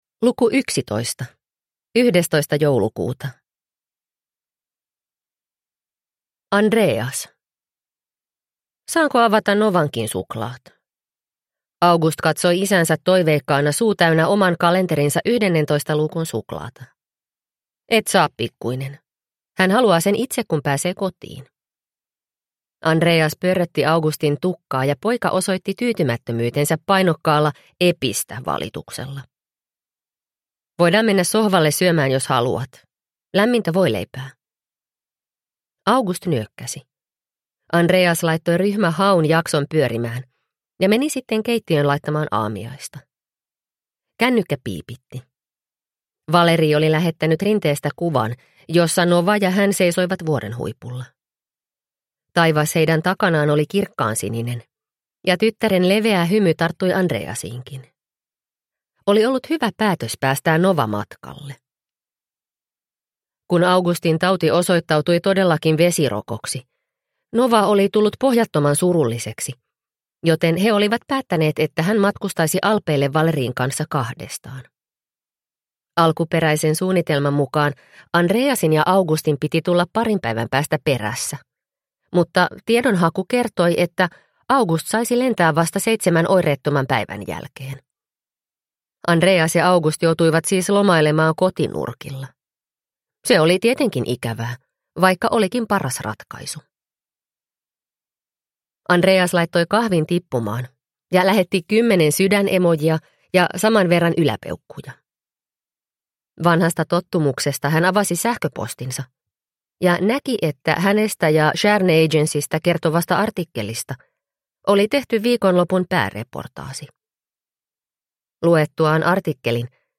Sankt Annan joulu – Ljudbok – Laddas ner